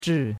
zhi4.mp3